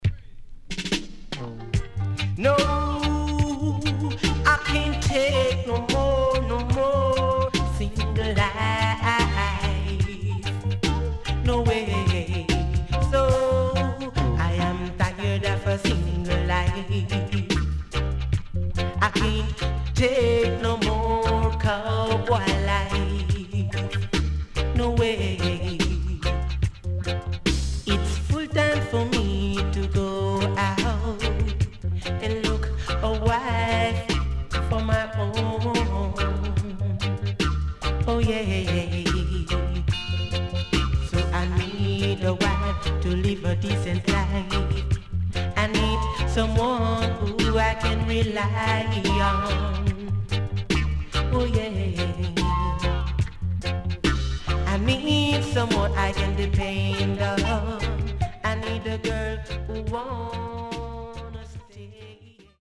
HOME > LP [DANCEHALL]
SIDE B:少しノイズ入りますが良好です。